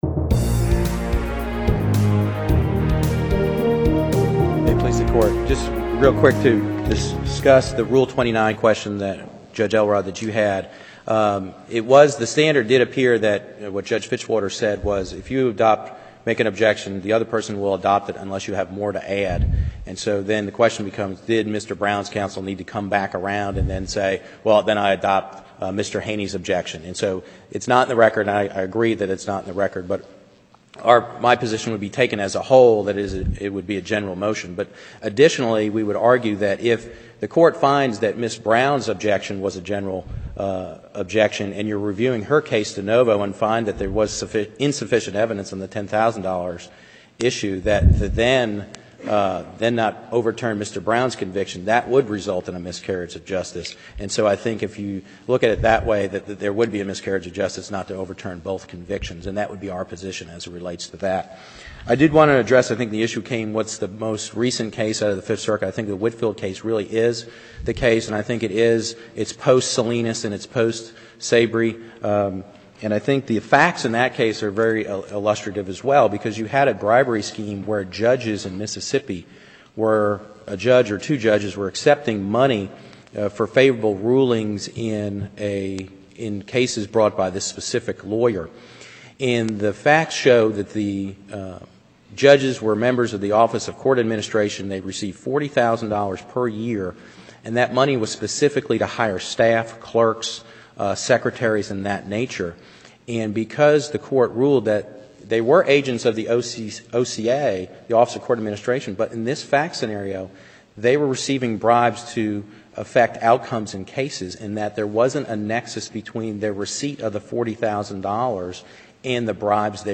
Audio Recordings of Oral Arguments
You can learn a lot about federal law by hearing defense attorneys arguing their cases in Federal Court.